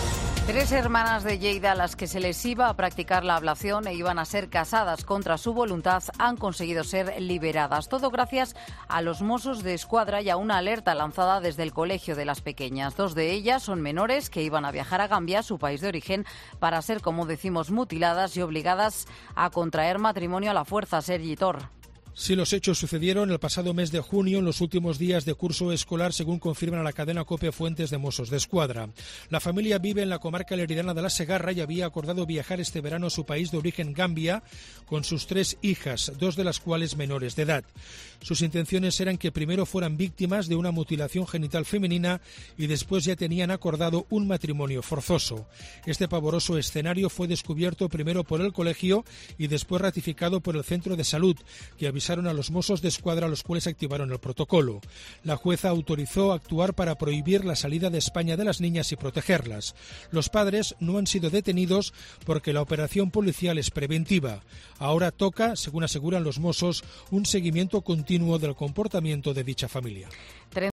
redactor de COPE lo explica